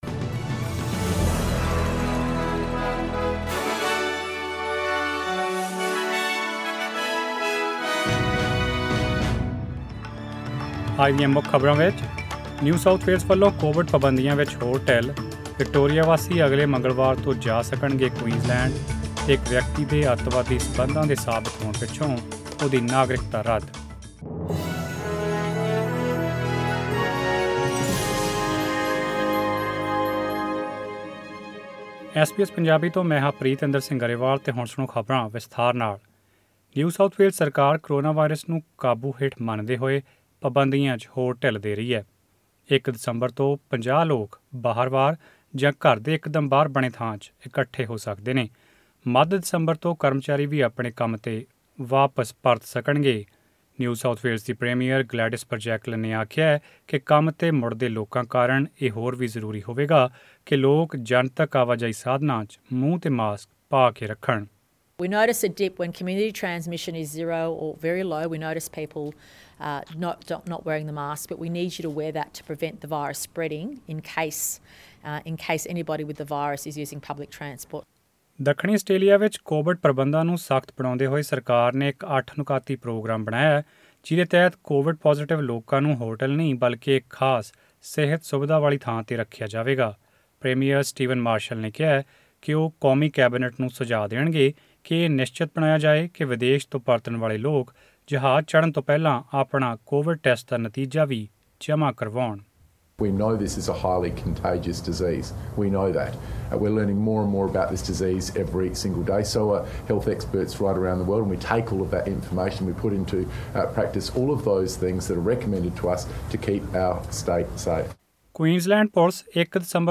Click the audio icon on the photo above to listen to the full bulletin in Punjabi People in Australia must stay at least 1.5 metres away from others.